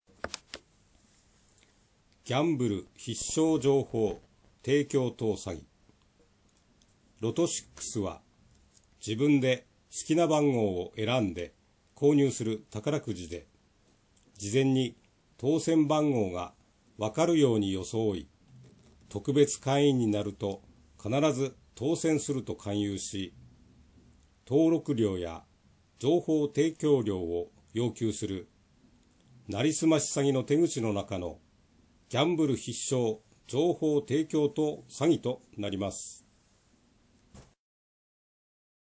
解説　読み上げMP3形式 0.9MB 0分40秒